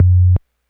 kick04.wav